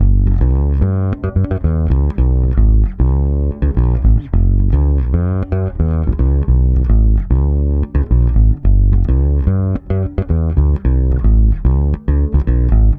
-AL AFRO G#.wav